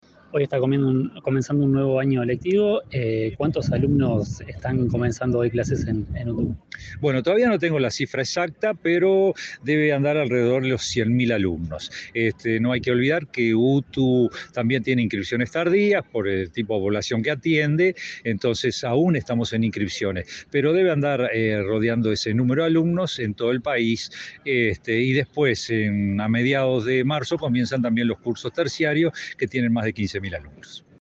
Entrevista al director general de Educación Técnica, Juan Pereyra
Este lunes 4, el director general de Educación Técnica (DGETP-UTU), Juan Pereyra, dialogó con Comunicación Presidencial en Durazno, sobre el inicio